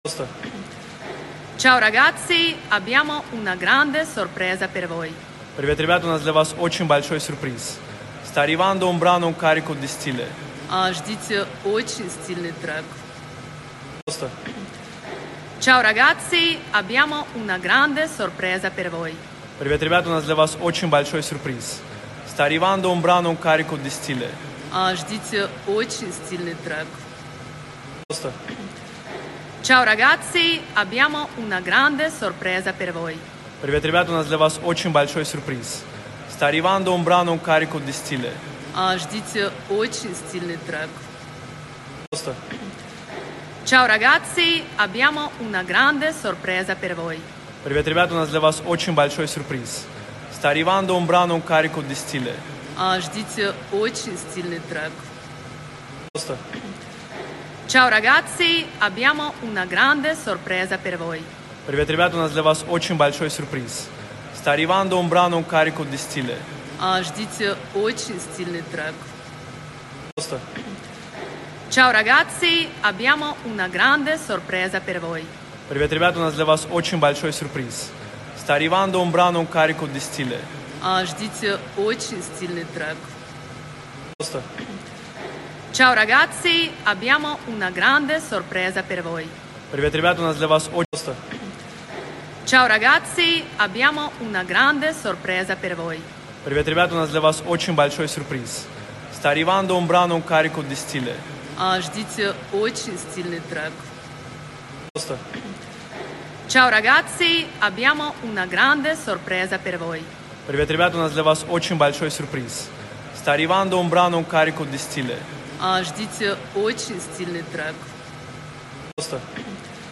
Русские поп песни, Рэп